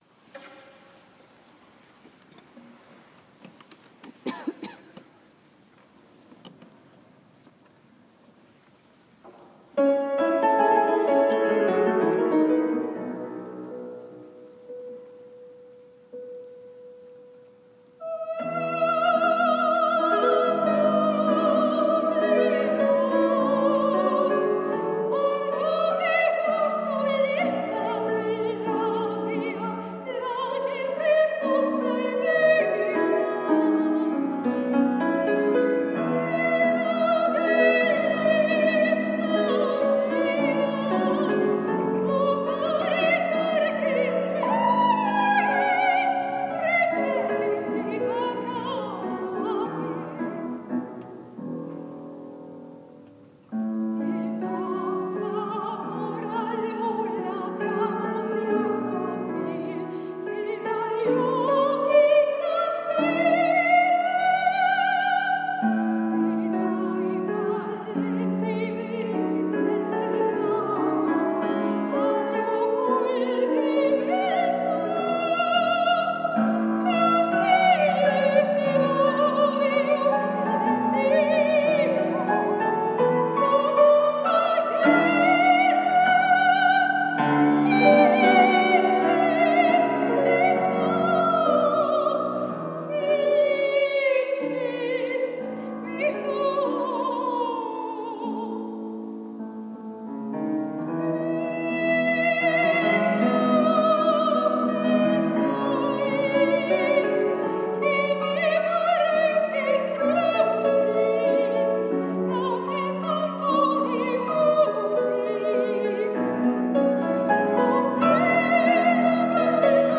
Röstfacklyrisk sopran/koloratursopran
“Quando m’en vo” (Musettas aria ur Bohème). Inspelat i Immanuelskyrkan 2016.